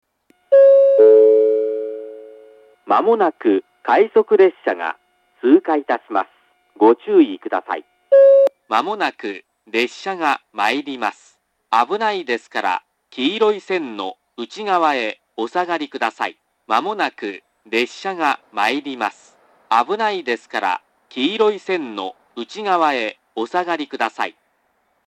発車ベルはありませんが、接近放送があります。
放送前のチャイムは流れず、いきなり放送が始まります。
かつては遠隔の詳細放送と従来からの簡易放送は異なるスピーカーから流れていましたが、現在は同じスピーカーから流れます。
１番線接近放送 接近予告放送が流れている途中から流れ始めています。
shinano-kizaki-1bannsenn-sekkinn1.mp3